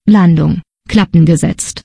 Text to Speech wie Pausen zw, Wörten Hilfe
Durch Enter drücken kannst du Zeilen einfügen. Aber ich finde die Pause zu lang.